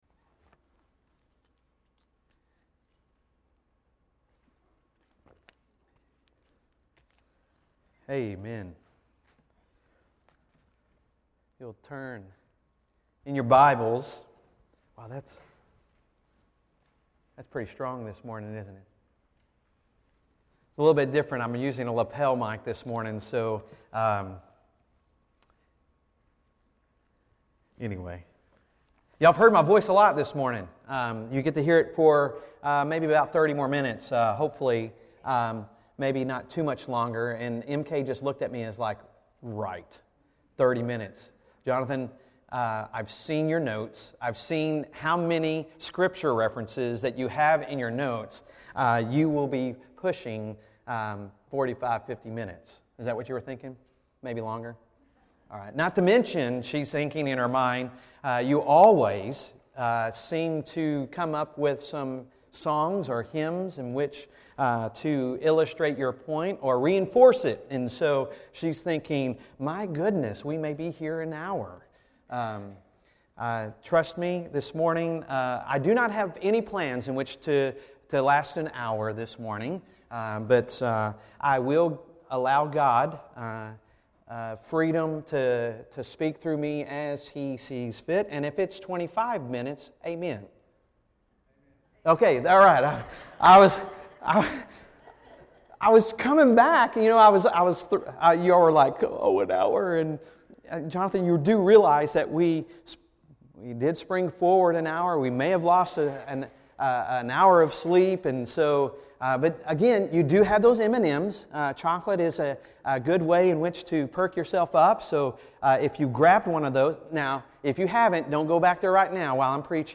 Sunday Sermon March 8, 2020